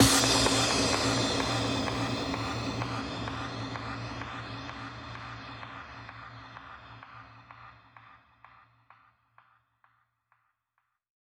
VES2 FX Impact 60.wav